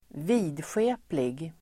Folkets service: vidskeplig vidskeplig adjektiv, superstitious Uttal: [²v'i:dsje:plig] Böjningar: vidskepligt, vidskepliga Synonymer: skrockfull Definition: som tror på övernaturliga saker, skrockfull